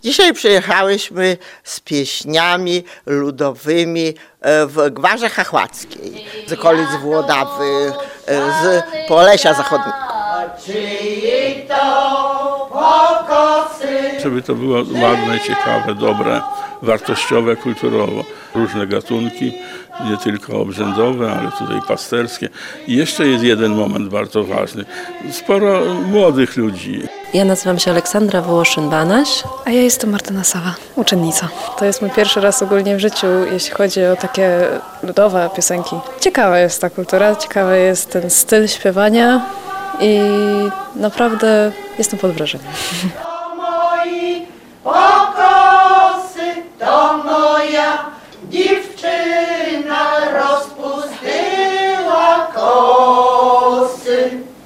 W Lublinie trwa Wojewódzki Przegląd Kapel i Śpiewaków Ludowych.